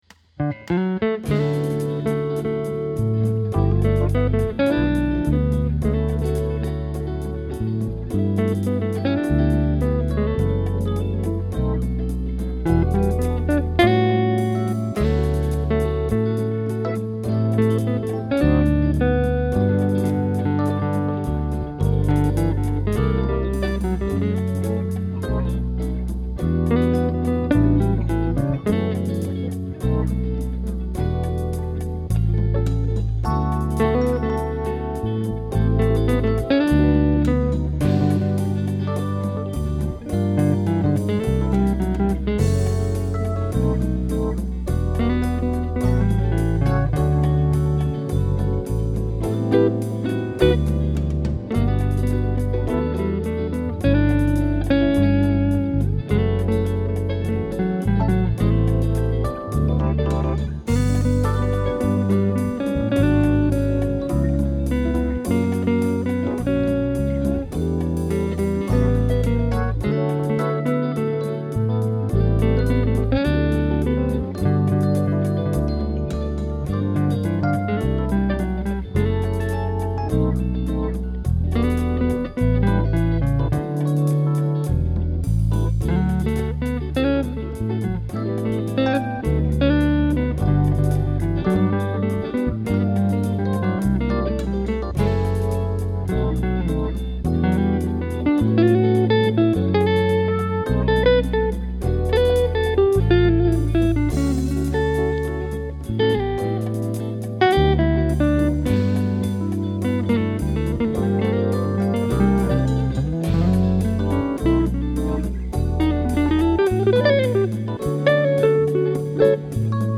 Guitar Works